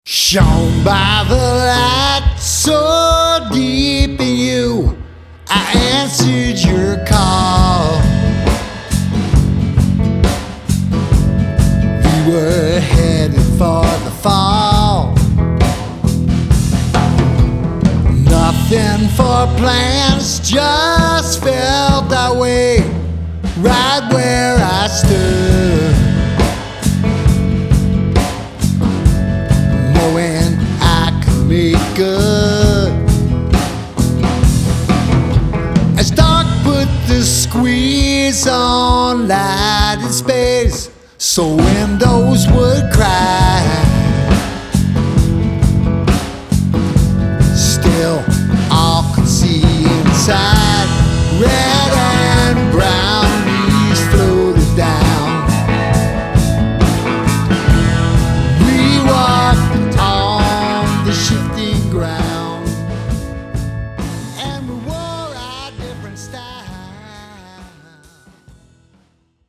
guitars, bass, drums, vocals
guitar, keyboards, electric sitar